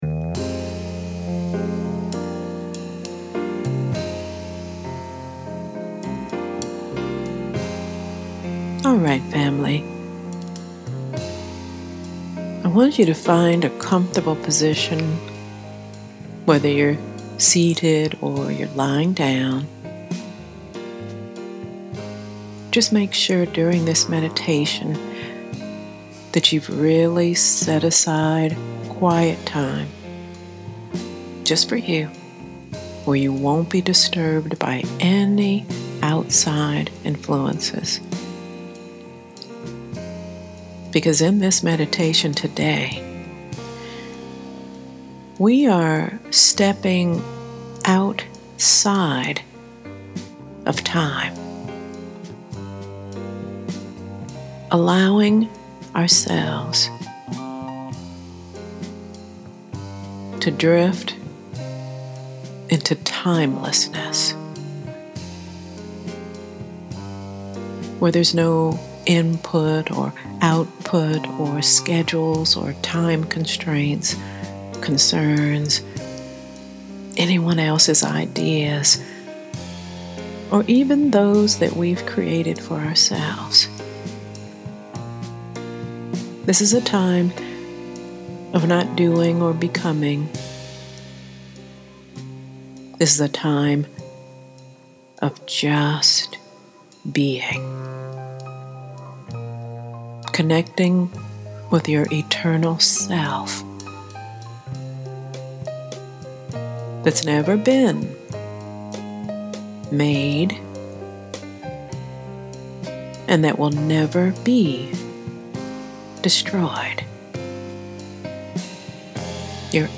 So here’s a meditation that guides you into timelessness, for a deeper connection with the Divine and the natural rhythms of life.